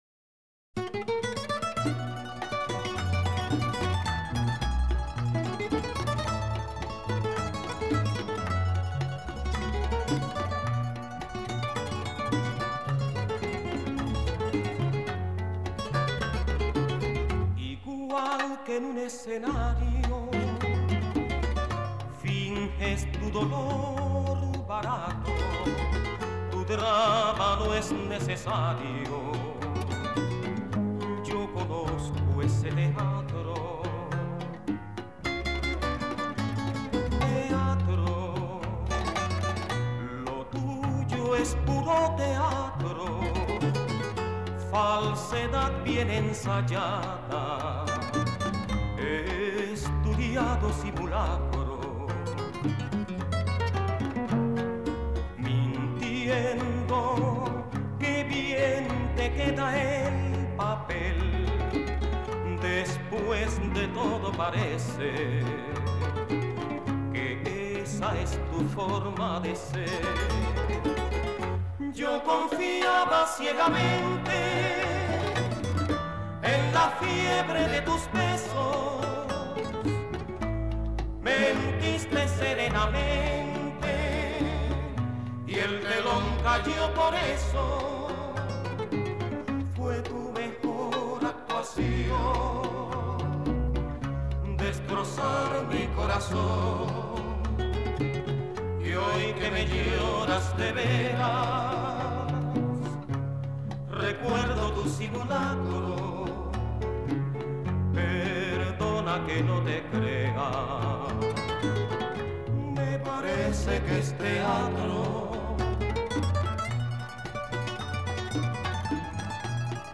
Tríos y Boleros